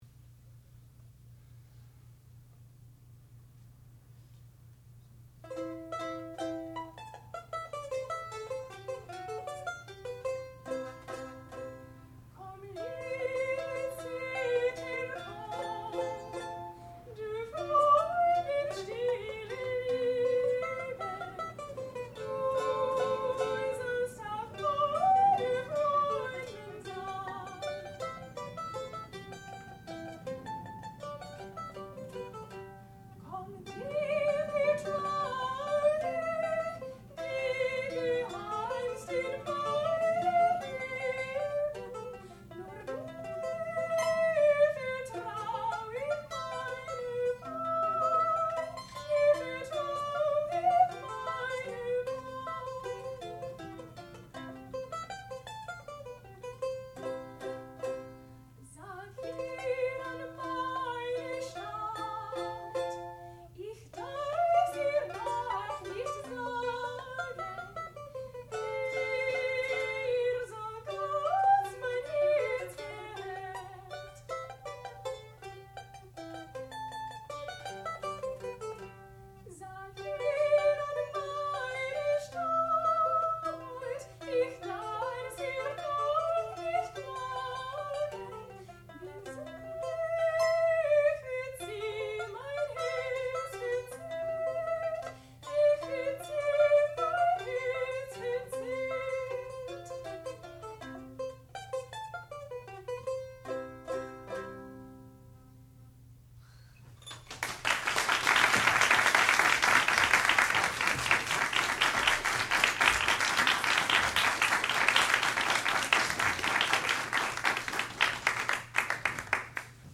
sound recording-musical
classical music
alto domra
mandolin
soprano